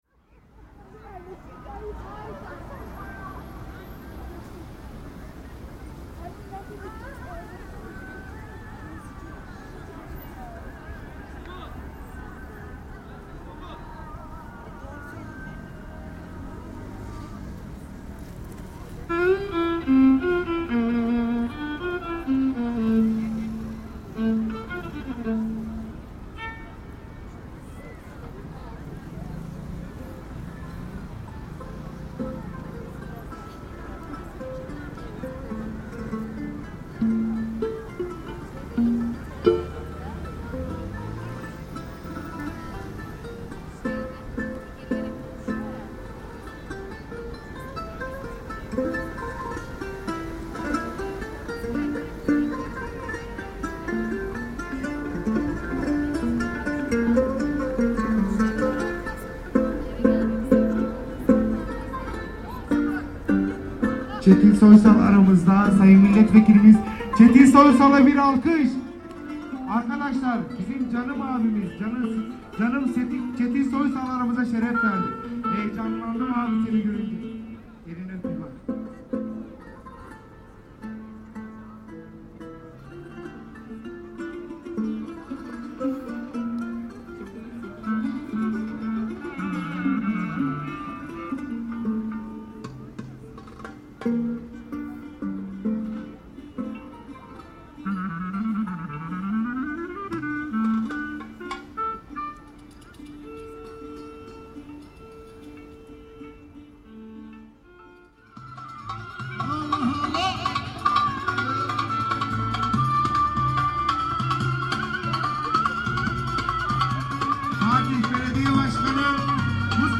The World Roma Day festivities have started at the shore of the Golden Horn (Haliç) strait.
The echoing ezan faintly heard in the begining of the recording made the festival pause for a few minutes. But soon the voices of the müezzins are no longer heard and the soundsystem is switched on.
The Çanakkale Lapseki Roman orchestra is warming up and doing soundcheck. The clarinetist Hüsnü Şenlendirici stolls on the stage and soon the air fills with the sound of Roma music. The evening continues with more and more performers, and the people are dancing and enjoying themseves despite the cooling evening weather.